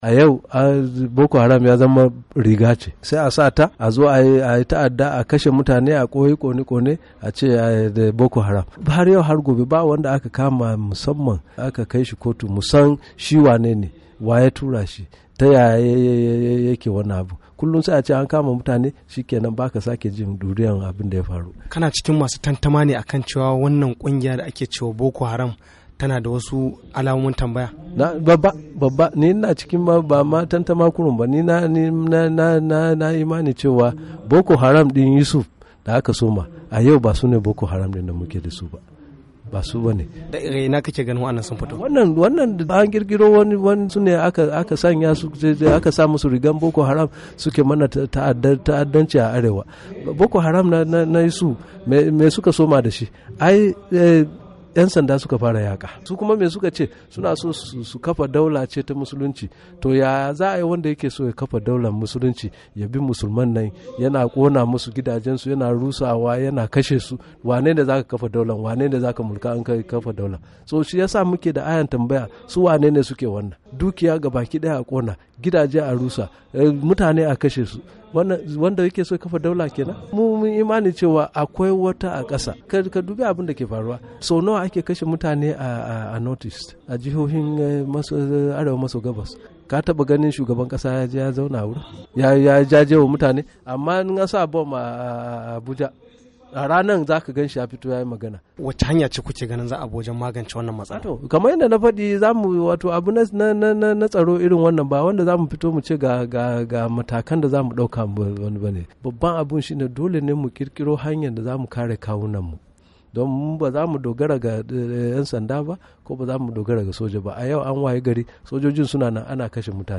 A firar da yayi da wakilin Muryar Amurka tsohon gwamnan jihar Kadun Kanal Hamid Ali yace kungiyar Boko Haram ta zama wata riga da ake sawa a yi ta'adanci ta koina da kuma a koyaushe